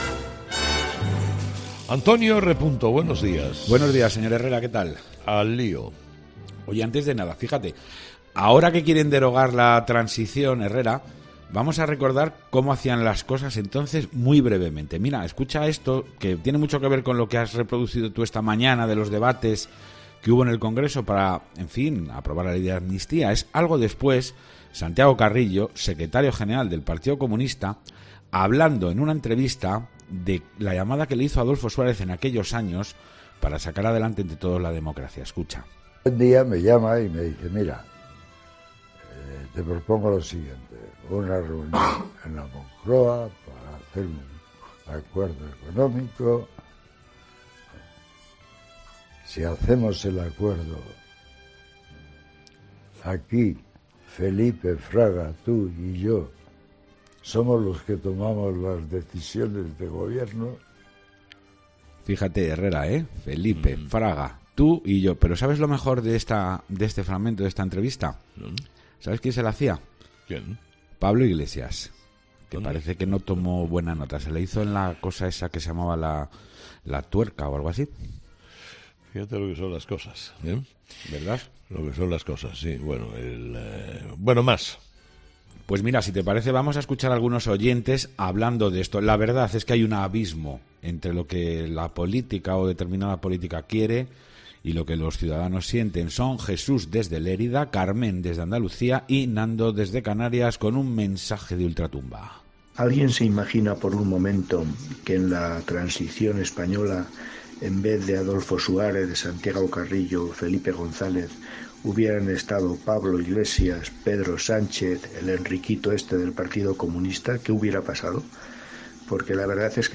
La tertulia de los oyentes
Con Carlos Herrera